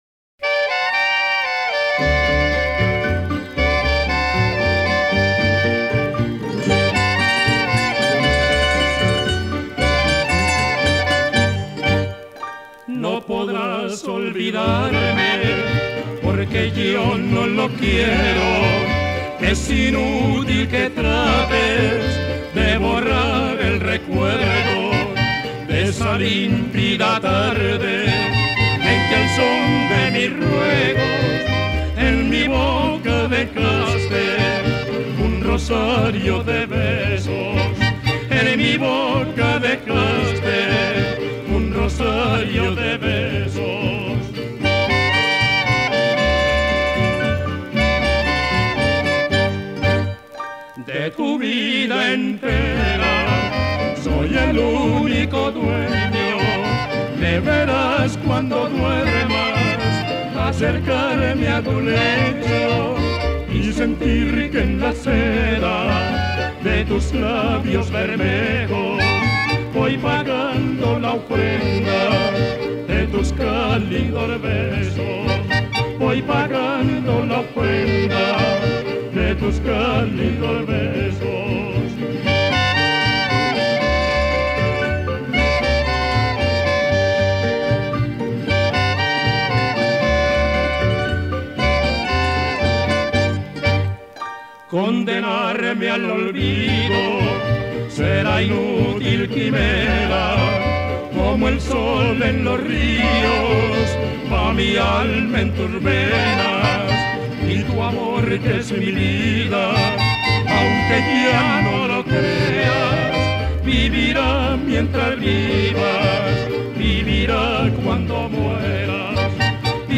Colombiana